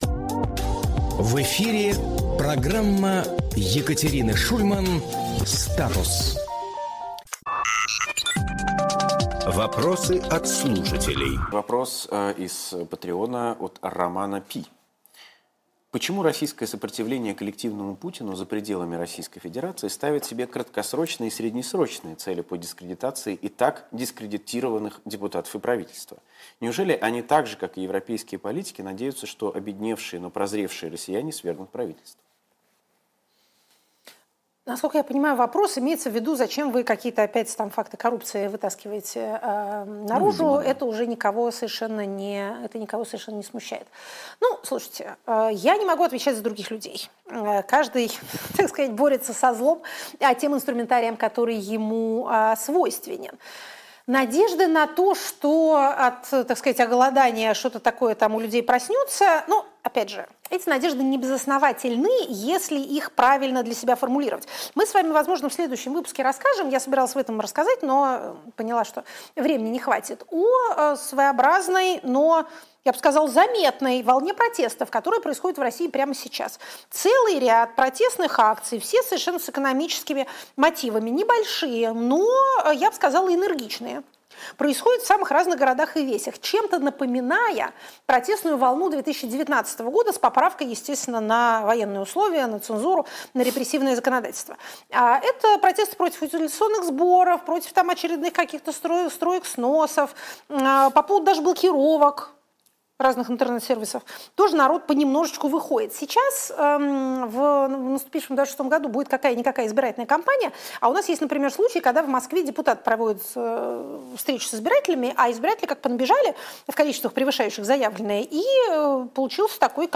Фрагмент эфира от 09.12.25